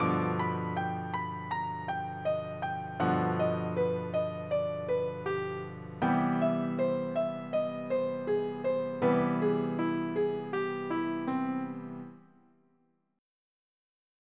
This fourth and final example is a pattern based on a concept called “triad pairs”.
The augmented scale can be deconstructed into two augmented triads a minor third apart.